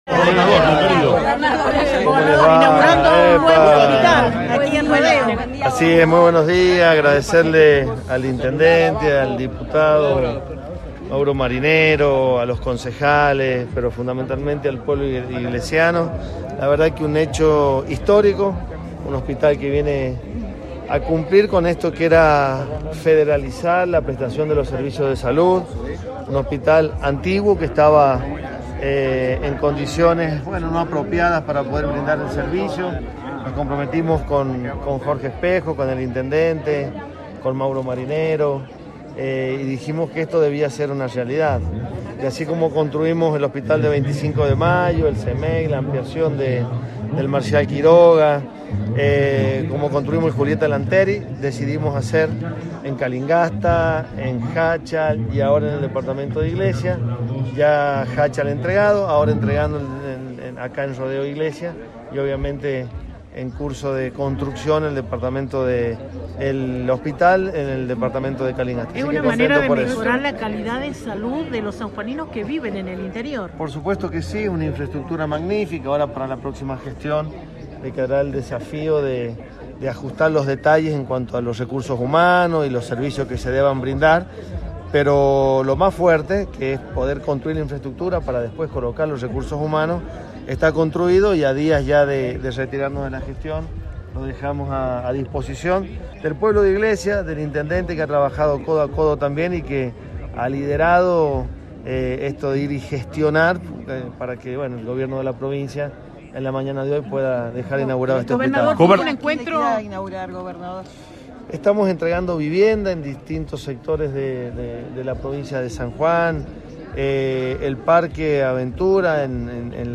INAUGURACIÓN HOSPITAL TOMAS PERÓN – DECLARACIONES GOBERNADOR SERGIO UÑAC
Declaraciones-gobernador.mp3